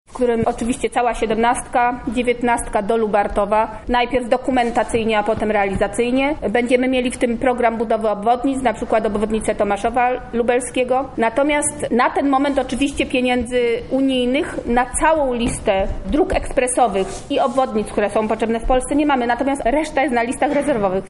Takie słowa wypowiedziała minister Elżbieta Bieńkowska podczas dzisiejszej wizyty w Lublinie.
– mówi minister Bieńkowska